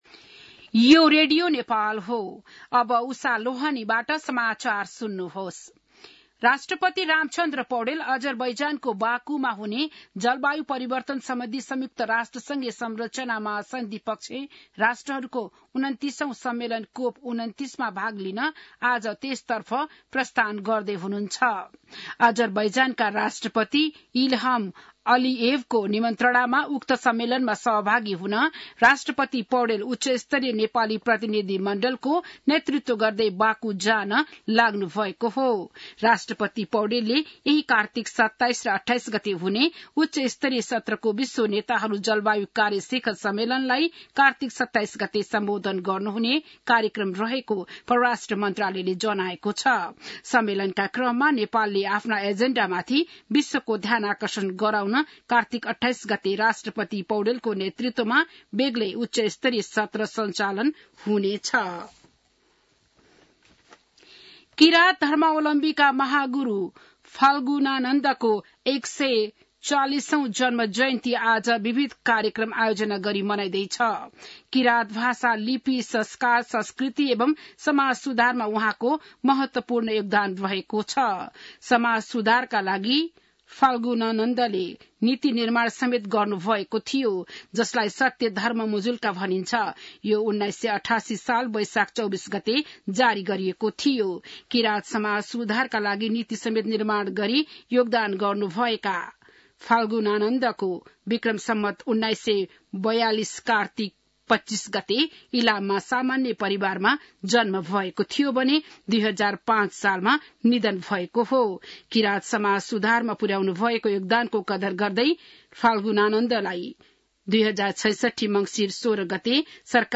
बिहान १० बजेको नेपाली समाचार : २६ कार्तिक , २०८१